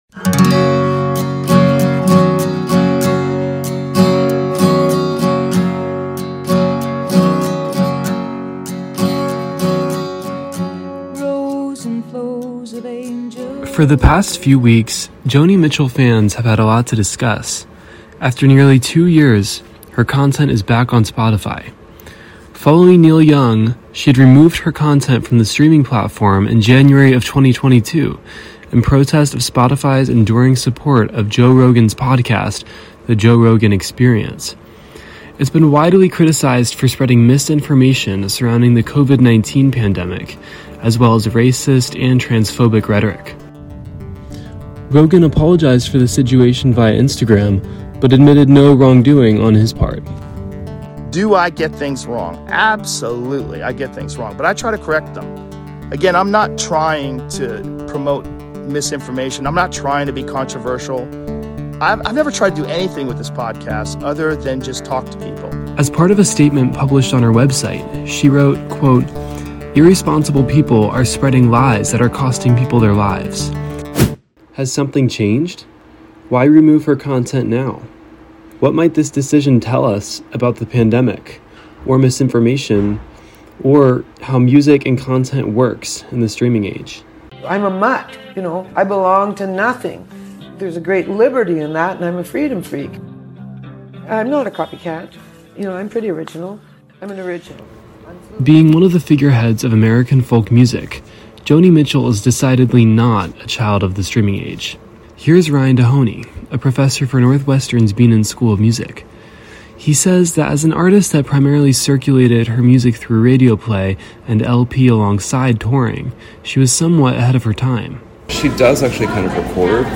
In the past few weeks, fans have been enjoying Joni Mitchell’s return to Spotify, after removing her content for two years in protest of the platform’s enduring support of Joe Rogan. Two professors weigh in on what this means for the pandemic, as well as the state of misinformation and streaming.